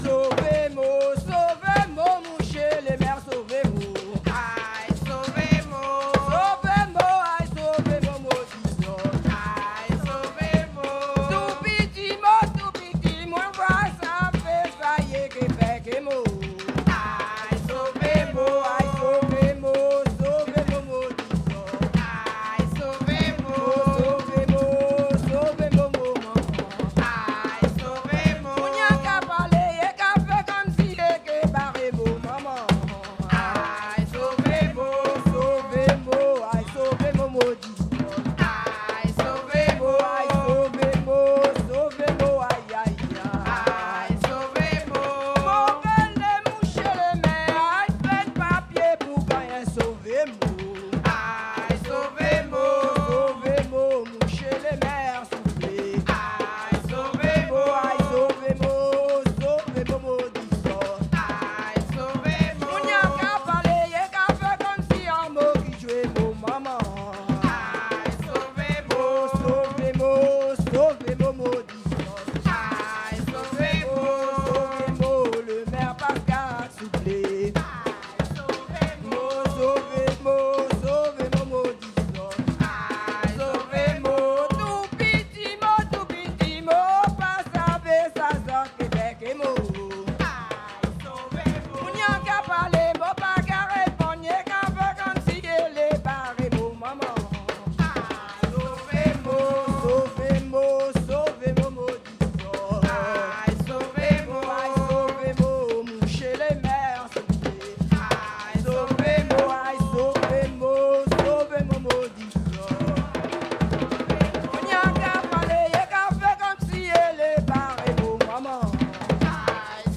Soirée Mémorial
danse : kasékò (créole)
Pièce musicale inédite